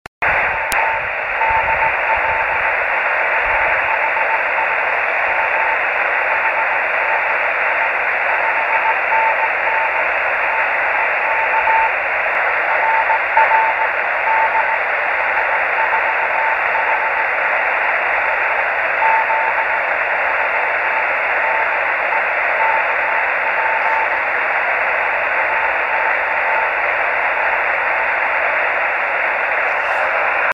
Grabaciones Rebote Lunar de KP4AO,
Radiotelescopio del Observatorio de Arecibo en Puerto Rico
Equipo: Yaesu FT-817.
Antena: Yagi 9 elementos para 432 MHz en boom de madera de 1 m de largo.
1-cw.mp3